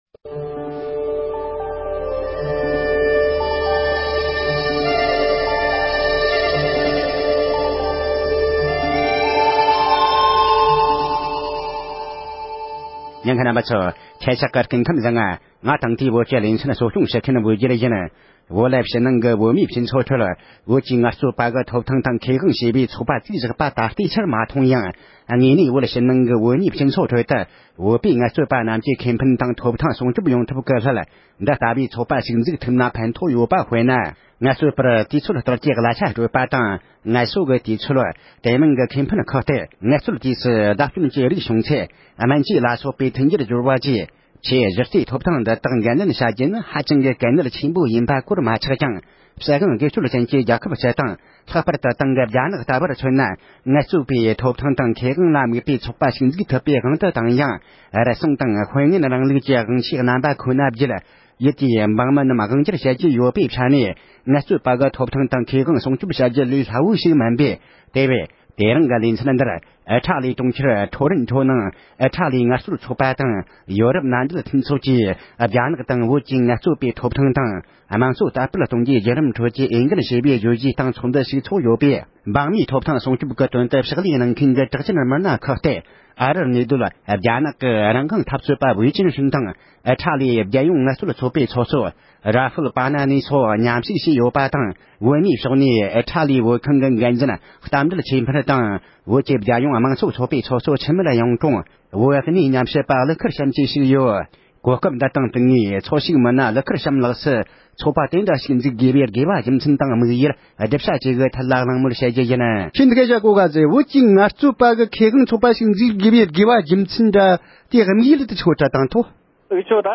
བོད་ཀྱི་ངལ་རྩོལ་ཁེ་དབང་ཚོགས་པའི་དམིགས་སྒོ་དང་འབྲེལ་བའི་གནས་ཚུལ་ཁག་གི་ཐད་གླེང་མོལ།